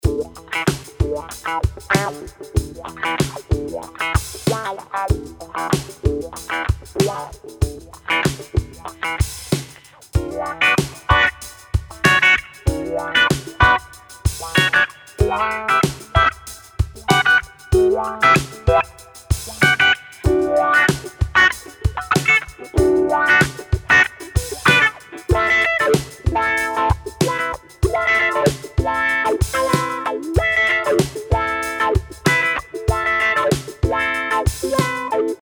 A collection of funky, wah wah guitar riffs.
NBM0169-Wah-Wah-Guitar-Vol-1.mp3